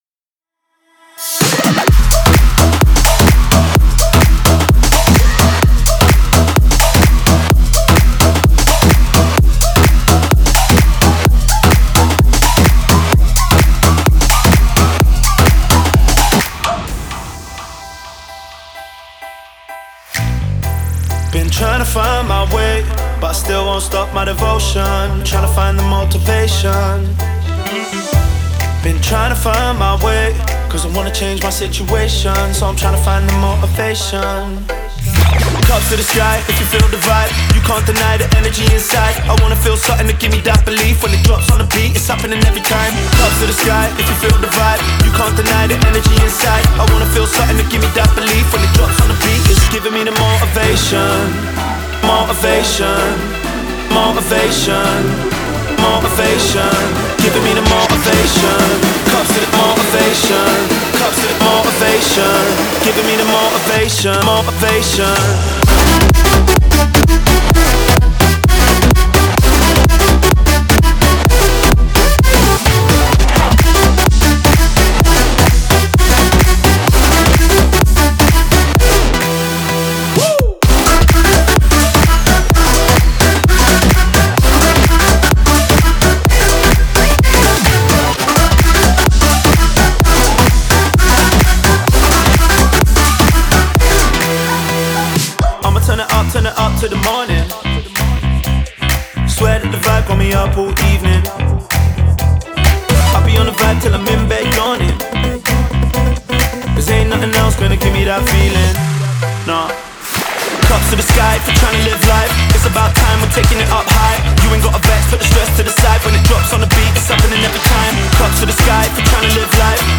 Future Bounce